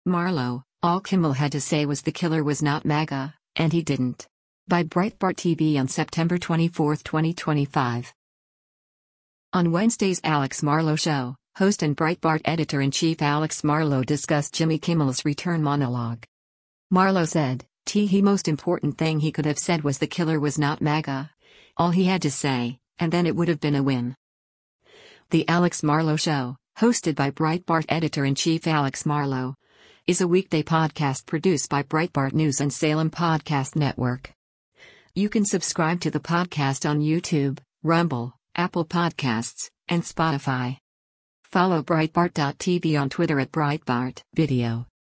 On Wednesday’s “Alex Marlow Show,” host and Breitbart Editor-in-Chief Alex Marlow discussed Jimmy Kimmel’s return monologue.